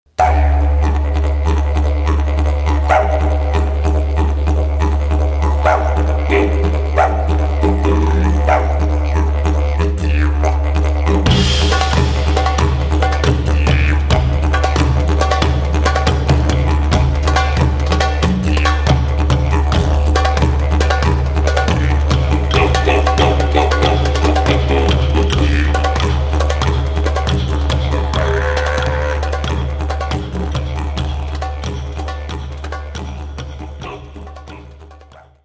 Muzika po nodropedsku, tentokrát s australským nádechem
Hned ve tmě rozbalil nástroj z pravého eukaliptu a přímo u táboráku zahrál připravujícím se Nodropedům do sauny. Jak takový mistrovský výkon na didgeridoo vypadá si můžete poslechnout
didge.mp3